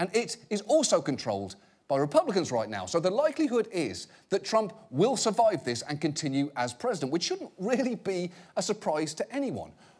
example_male_voice_9_seconds.wav